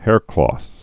(hârklôth, -klŏth)